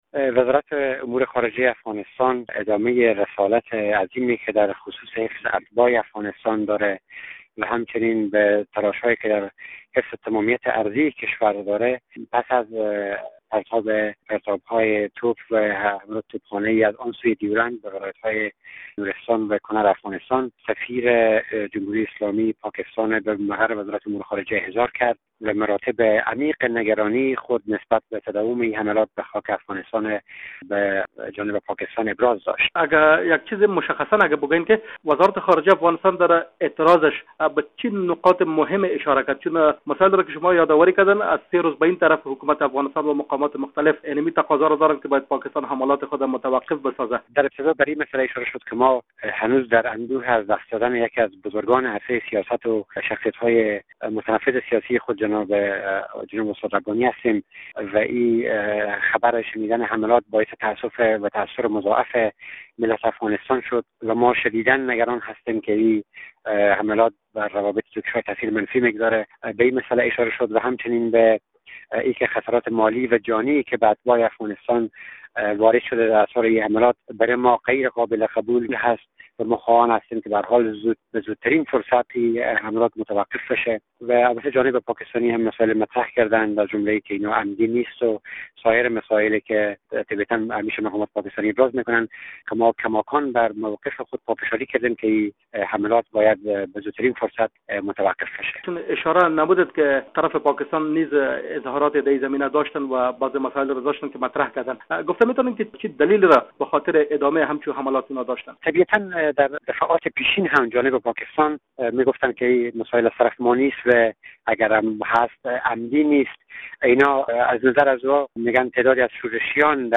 مصاحبه در مورد احضار سفیر پاکستان به وزارت خارجه افغانستان